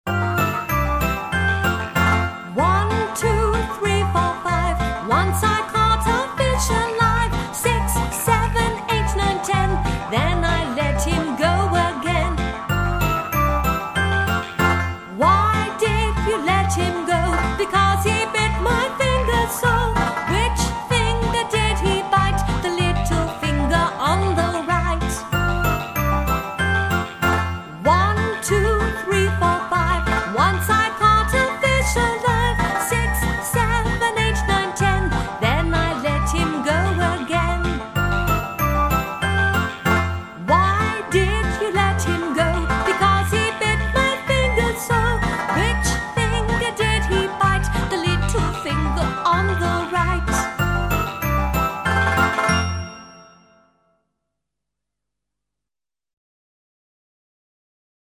One, Two, Three, Four, Five - английская песня-шутка - слушать онлайн